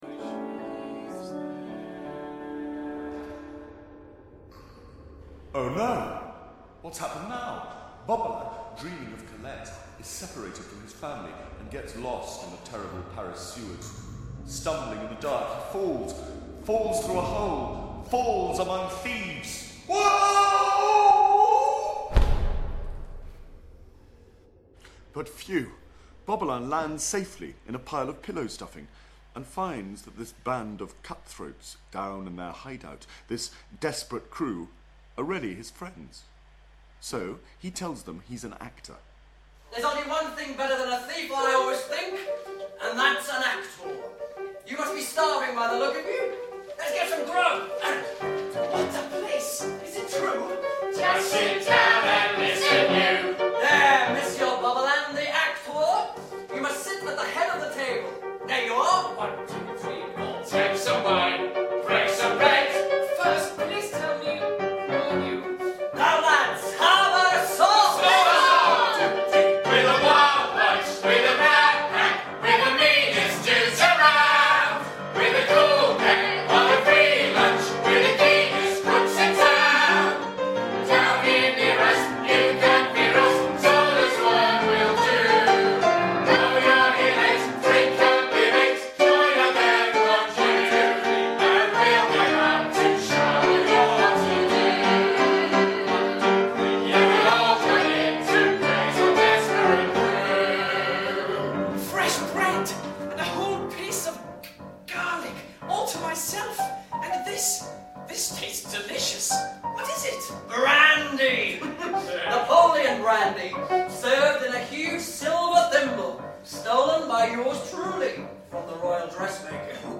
From the Royal Academy of Music Workshop.